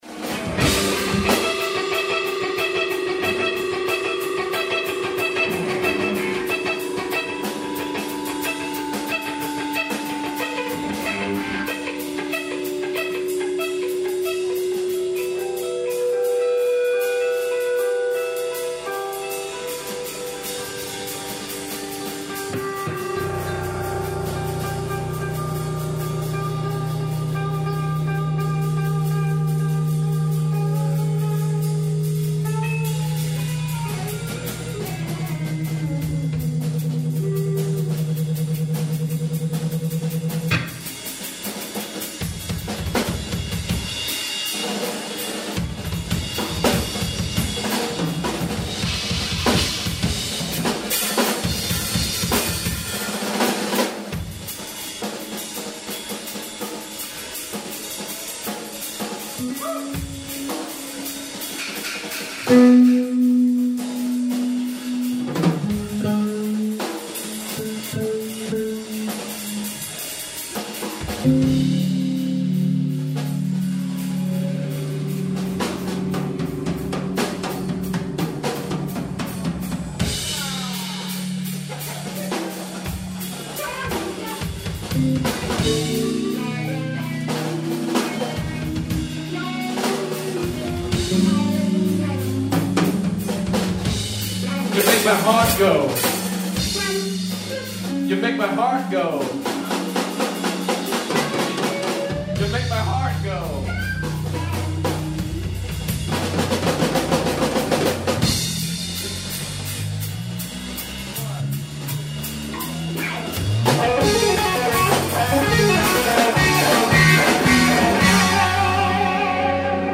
Recorded on 11/10/01 at Lynagh's, Lexington, KY
vocals, guitar
drums
bass